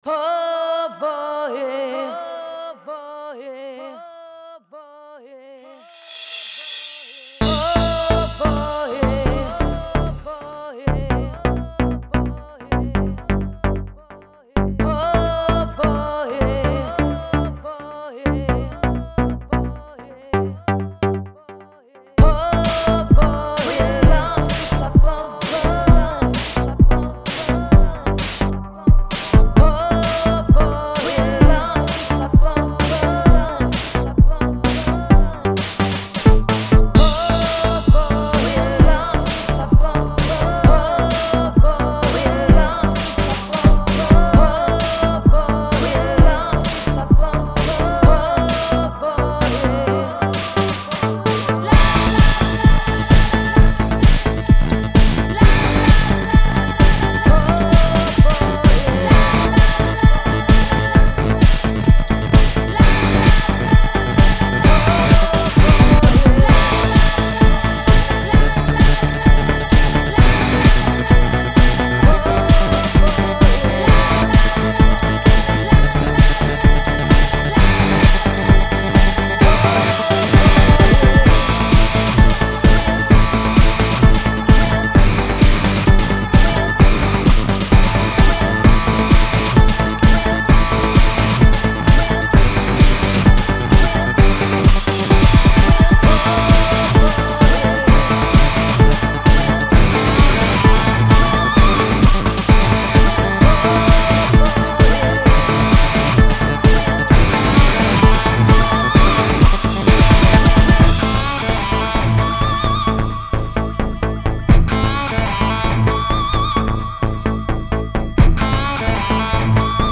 y un remix dance de